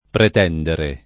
vai all'elenco alfabetico delle voci ingrandisci il carattere 100% rimpicciolisci il carattere stampa invia tramite posta elettronica codividi su Facebook pretendere [ pret $ ndere ] v.; pretendo [ pret $ ndo ] — coniug. come tendere